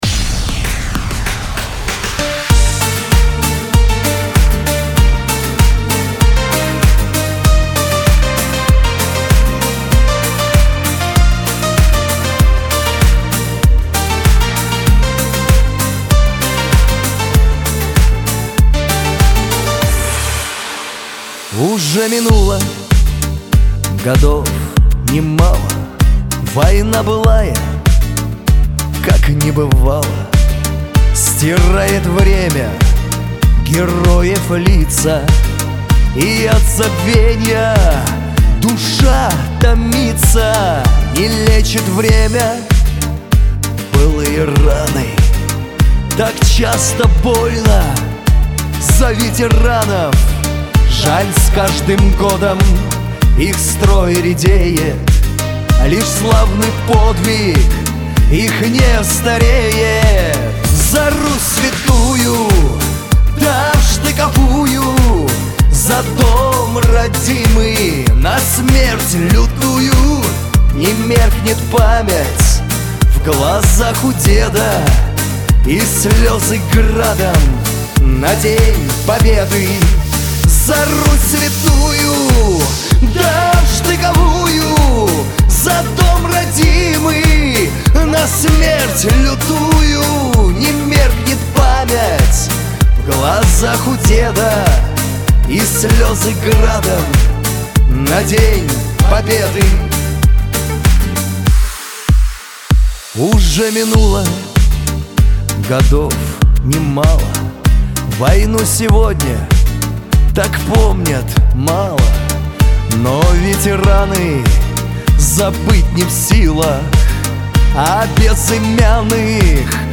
песня
3353 просмотра 1404 прослушивания 592 скачивания BPM: 97